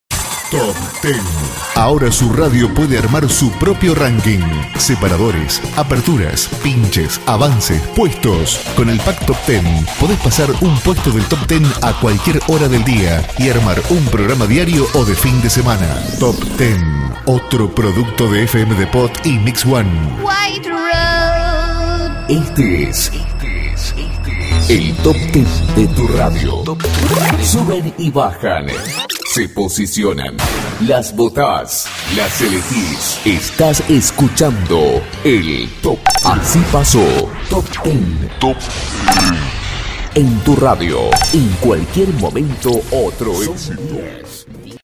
ARTE RADIAL - VESTIMENTA PARA EMISORAS - LOCUCIONES GENERICAS LOCUCIONES PERSONALIIZADAS - SPOTS PUBLICITARIOS - SEPARADORES - JINGLES